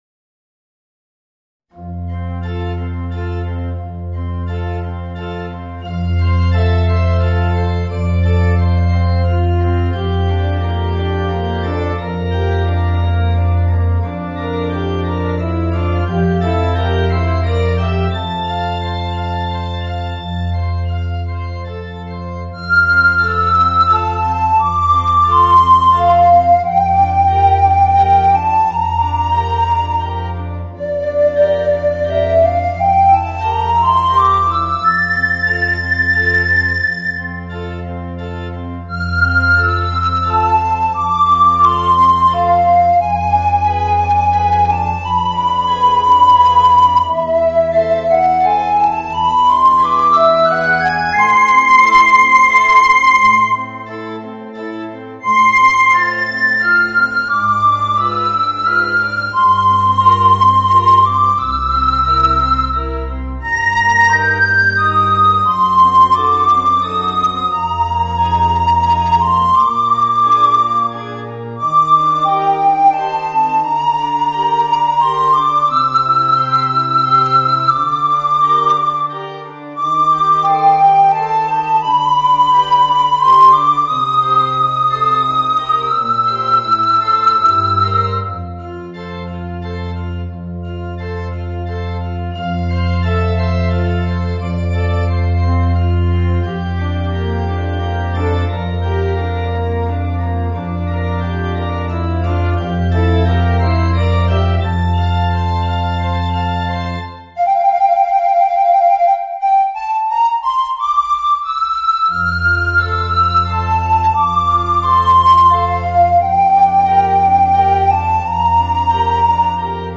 Voicing: Soprano Recorder and Organ